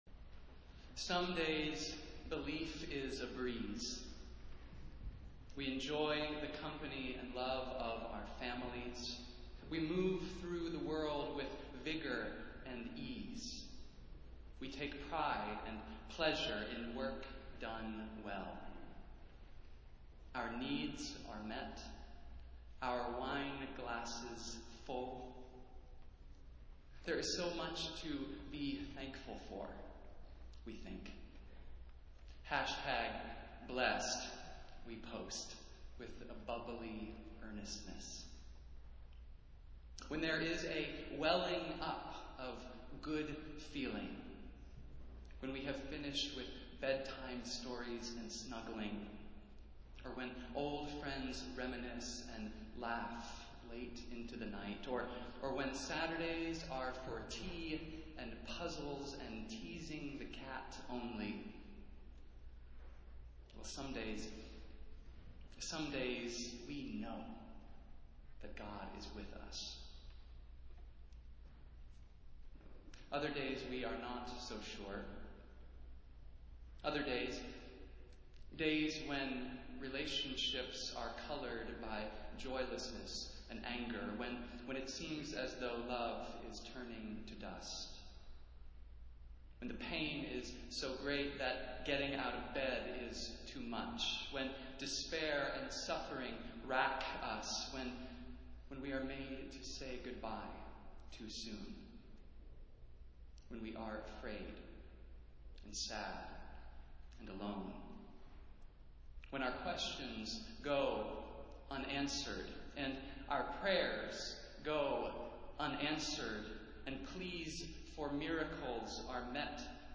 Festival Worship - Seventh Sunday after Epiphany